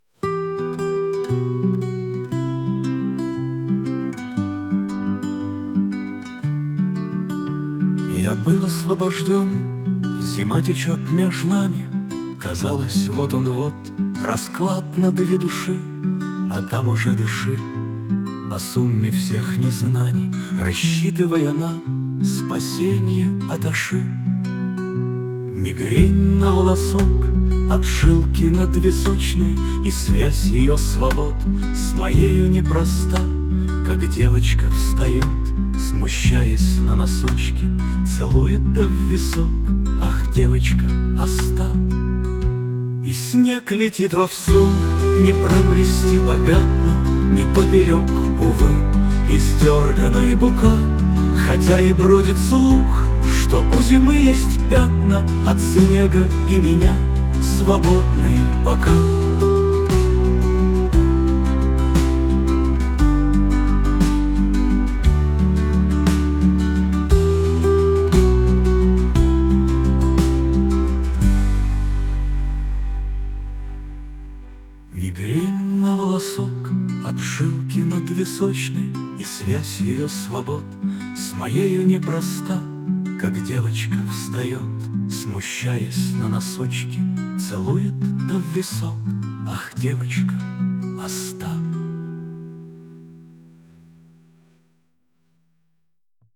Можно задать буквально строчку, выбрать стиль, инструмент, программа сама напишет песню.
Загрузил свой старый стишок, получил неожиданную версию, два варианта.